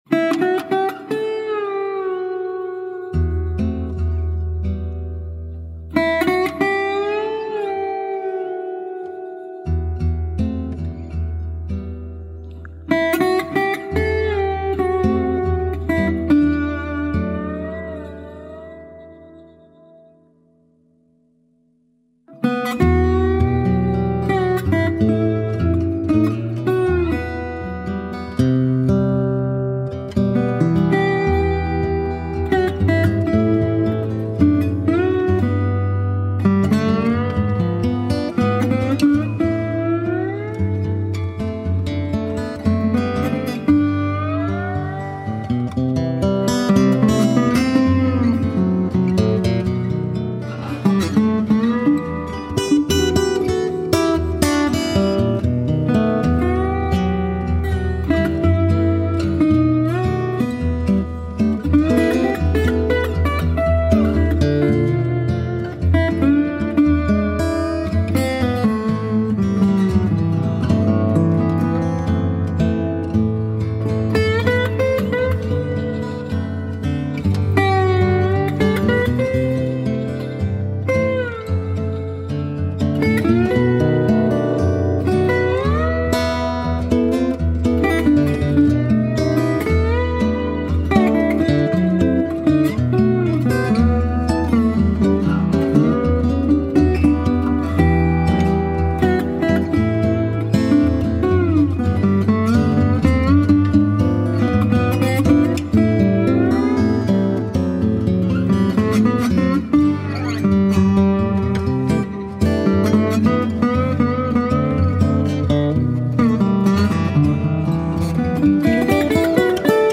improvised version
Brazilian jazz tune